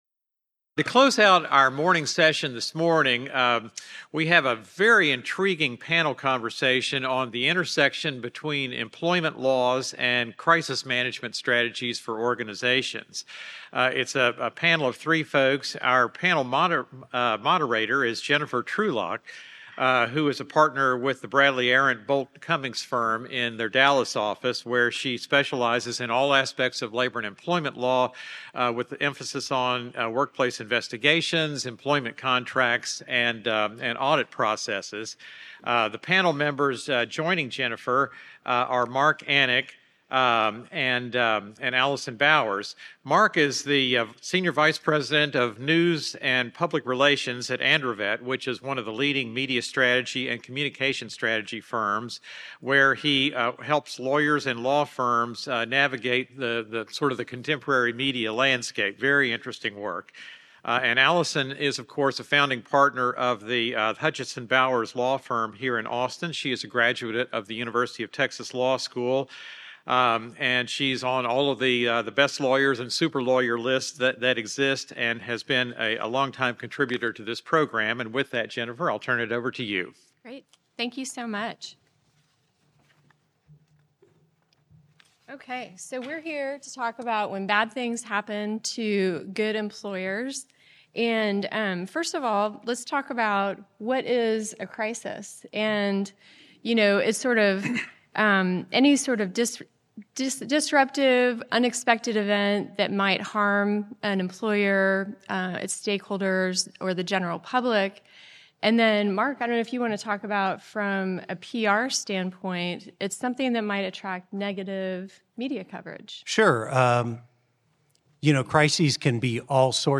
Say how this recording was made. Originally presented: May 2024 Labor and Employment Law Conference